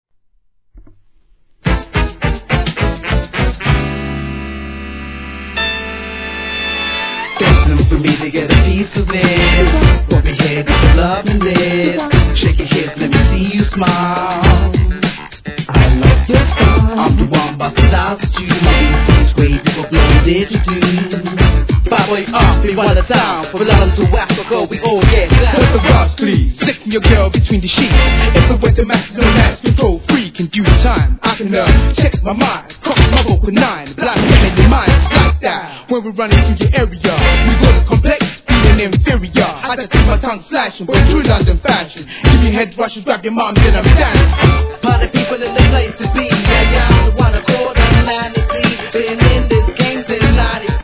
HIP HOP/R&B
使いのアーバンなダンシングチューン！！